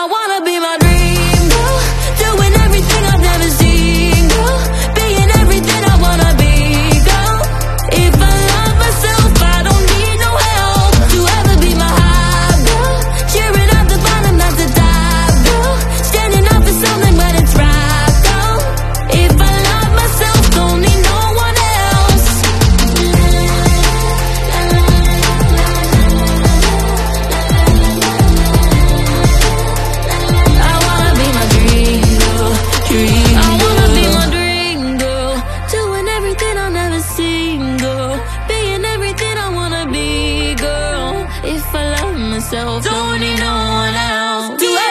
Alphabet sounds chant is now sound effects free download